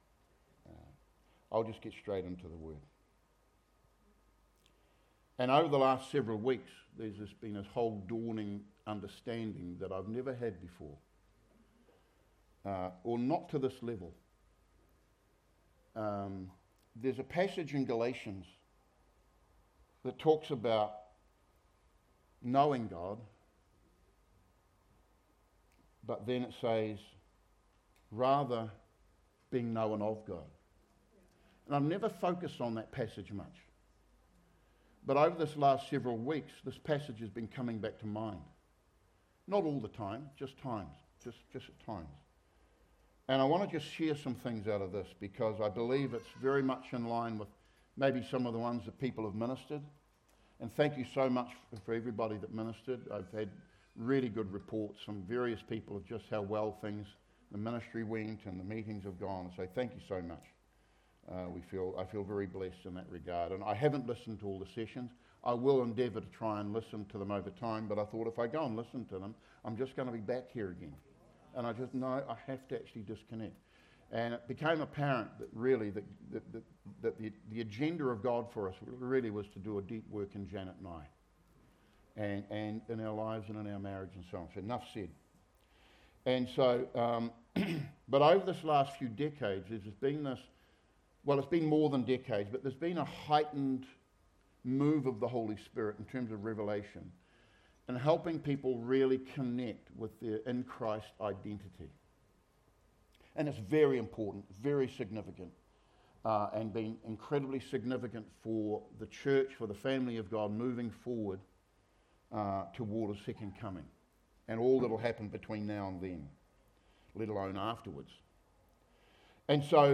Sermons | Living Waters Christian Centre